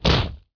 nobleman_hit.wav